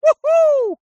Мужской клич у-ху